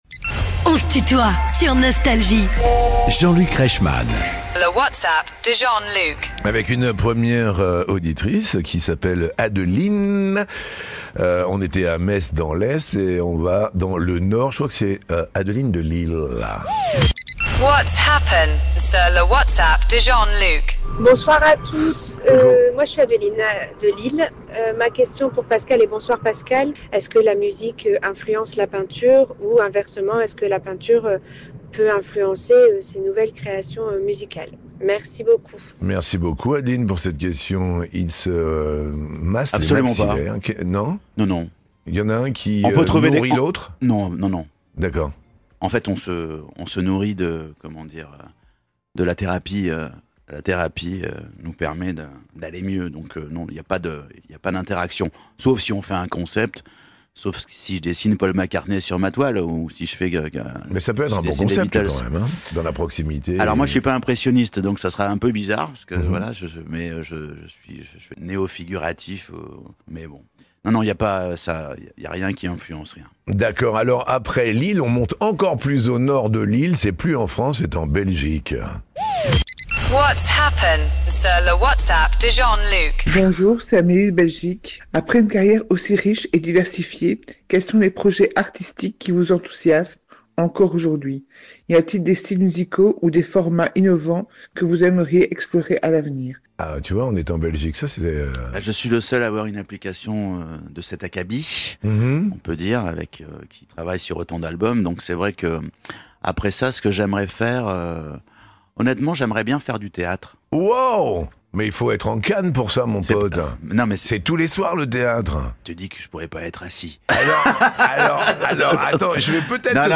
Les plus grands artistes sont en interview sur Nostalgie.
Pascal Obispo est l'invité de Jean-Luc Reichmann dans son émission